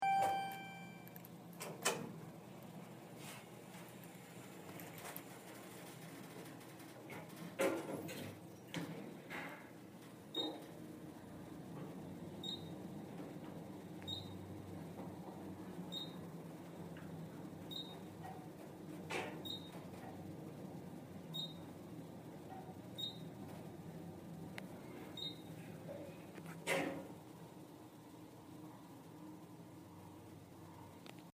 Field Recording #1
Location: Elevator in Constitution Hall
Sounds Heard: Elevator opening and closing, bells upon arrival, beeps for each floor, scratching, scraping noises of the elevator moving.
Feild-Recording-Elevator-1.mp3